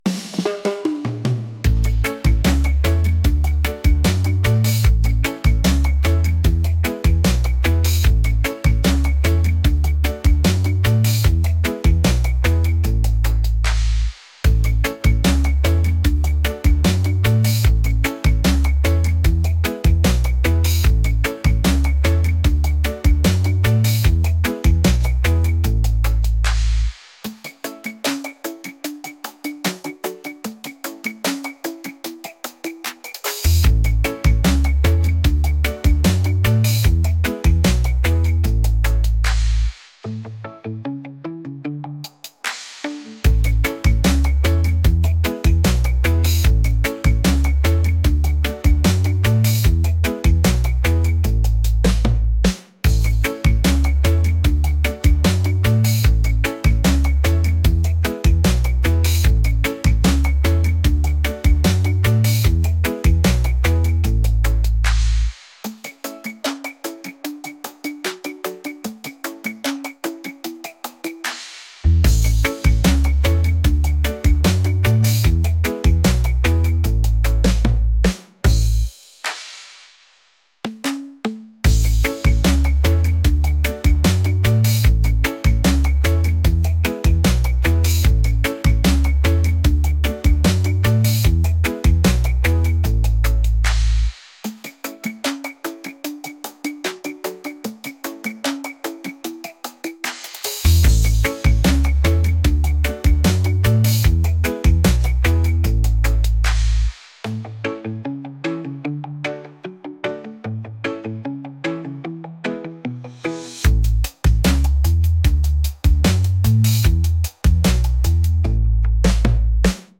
reggae | pop | latin